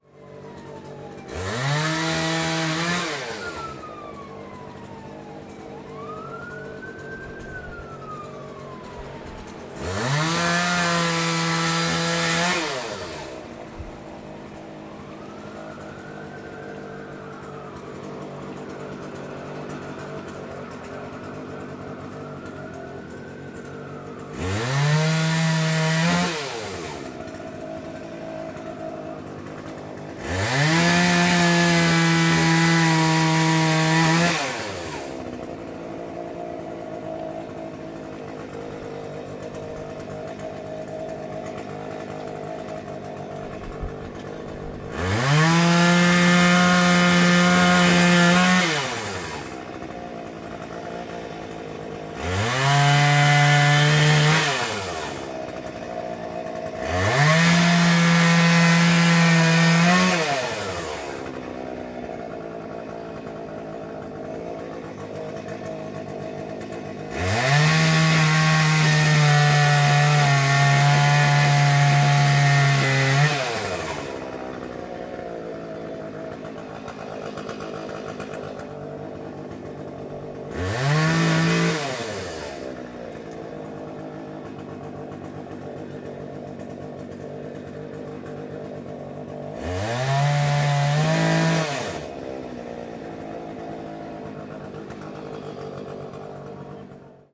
电锯
描述：电锯切割到树上。
标签： 电锯
声道立体声